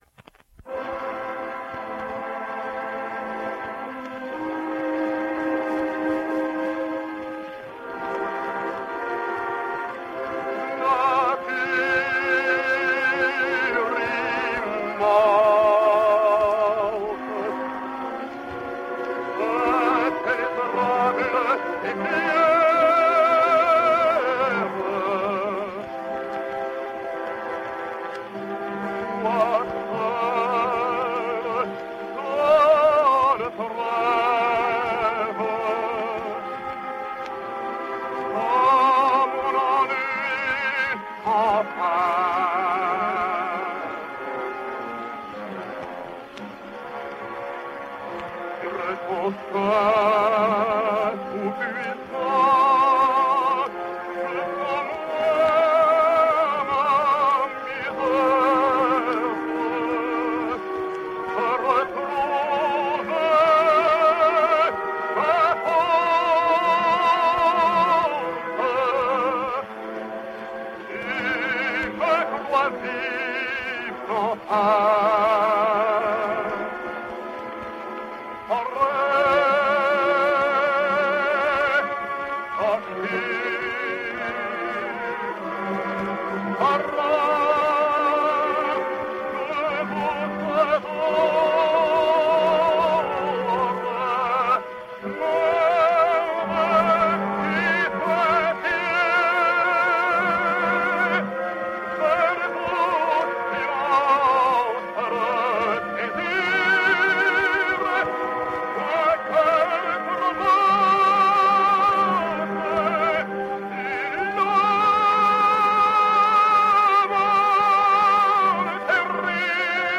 French Tenor.